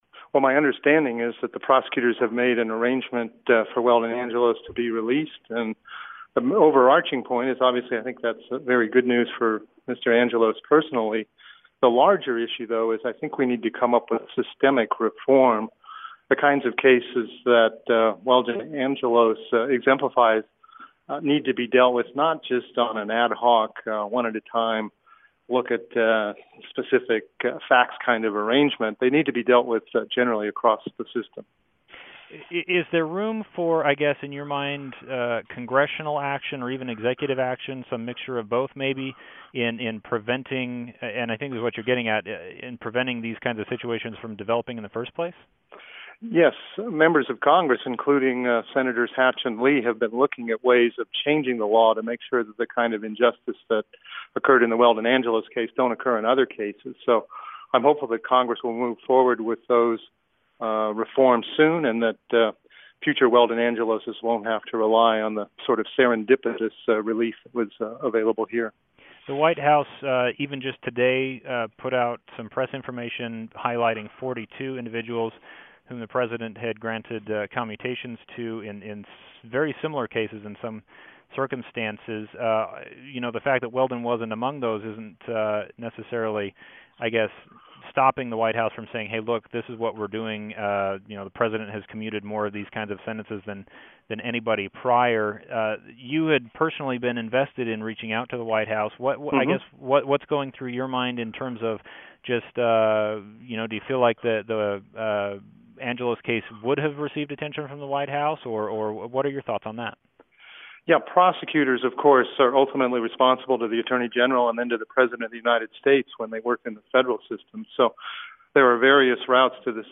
A Utah man sent to prison for 55 years in a drug case has been released, following a campaign for clemency. Former federal judge Paul Cassell explains how it happened.